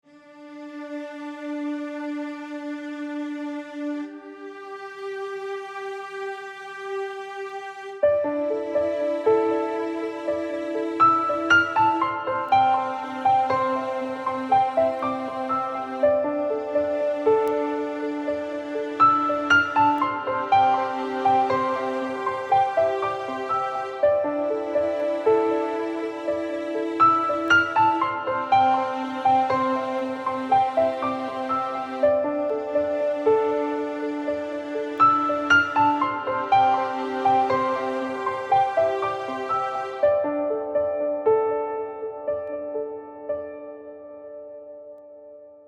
Category: Classical